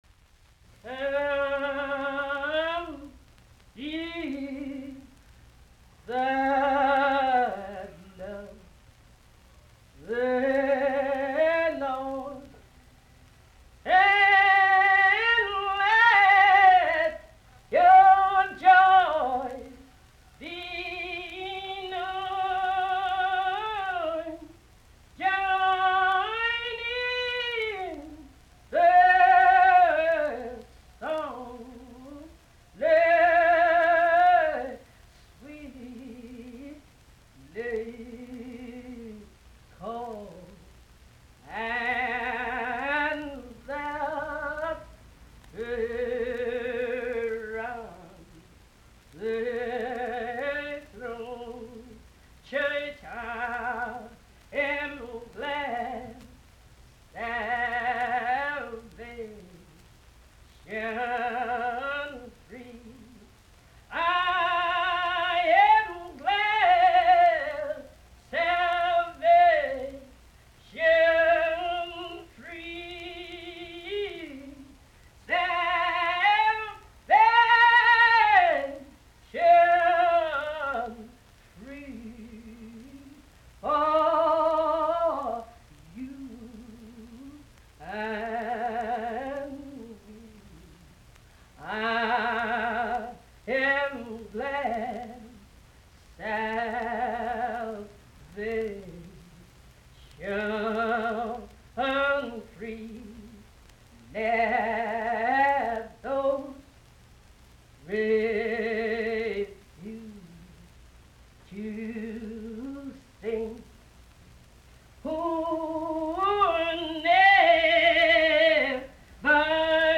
Music from the south : field recordings taken in Alabama, Lousiana and Mississippi.
Prayer (Chanted Invocation)